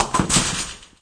bowling-1.wav